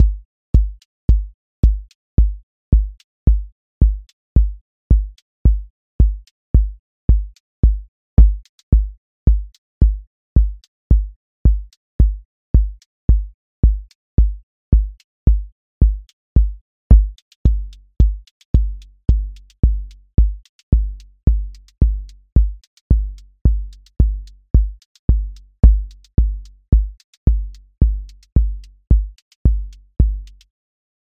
QA Listening Test house Template: four_on_floor
house four on floor 30s
• voice_kick_808
• voice_hat_rimshot
• voice_sub_pulse
• tone_warm_body
• motion_drift_slow